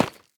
Minecraft Version Minecraft Version snapshot Latest Release | Latest Snapshot snapshot / assets / minecraft / sounds / block / tuff_bricks / place1.ogg Compare With Compare With Latest Release | Latest Snapshot